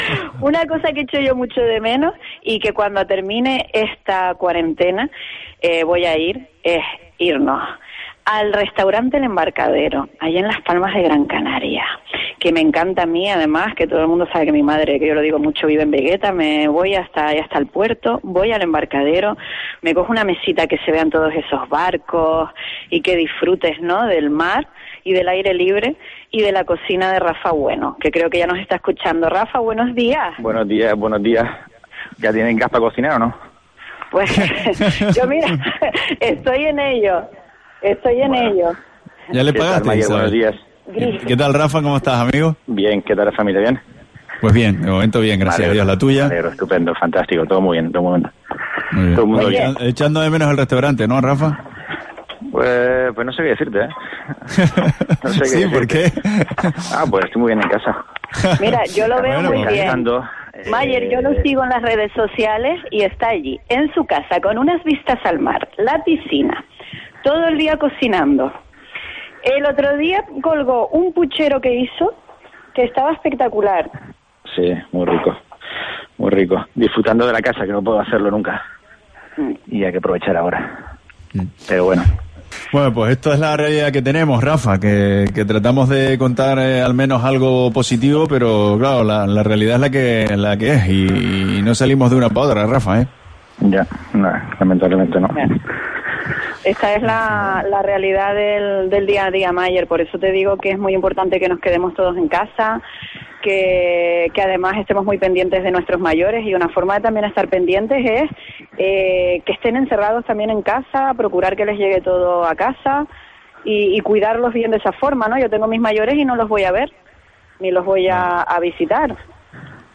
Entrevista Restaurante El Embarcadero